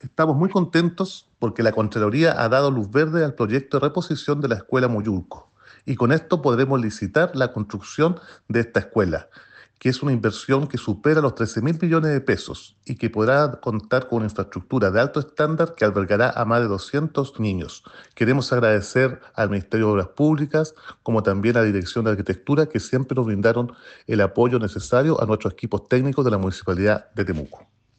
Roberto-Neira-alcalde-Temuco-Mollulco.mp3